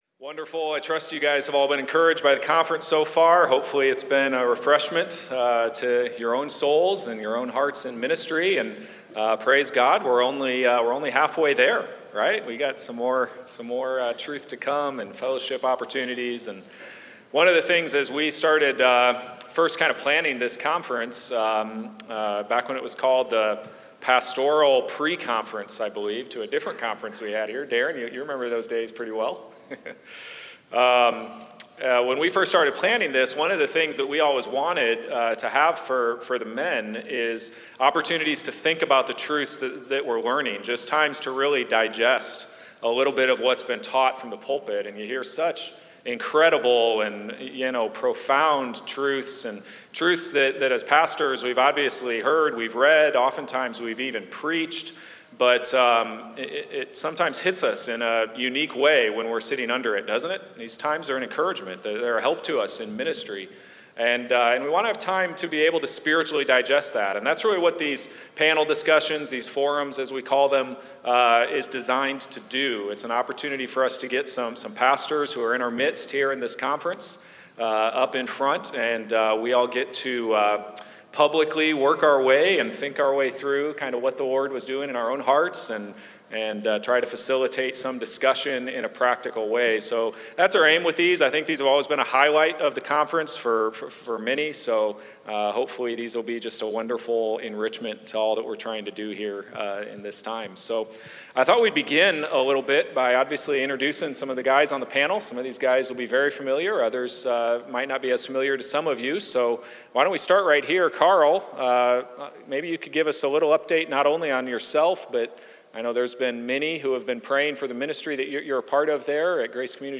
Forum Discussion 1